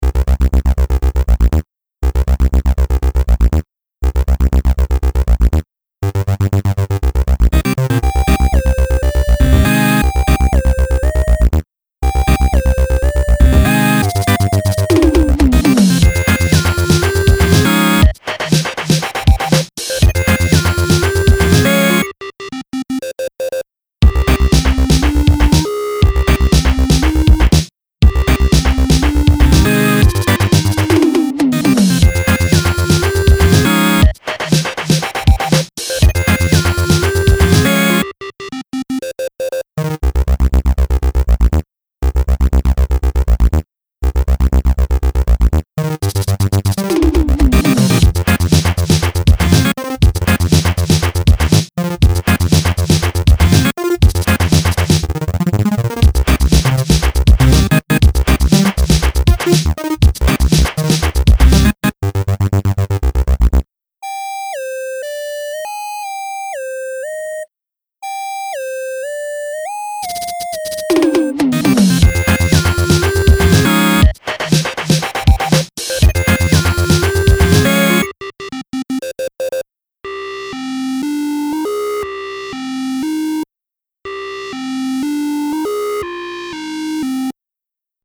Noisy bass driven 8-bit computer gaming electronica.